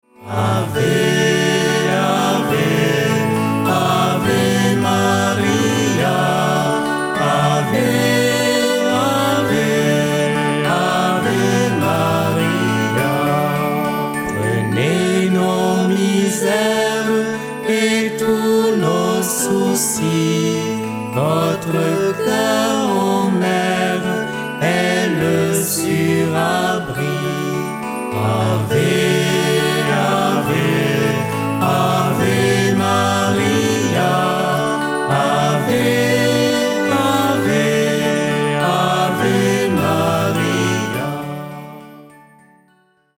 Louange (431)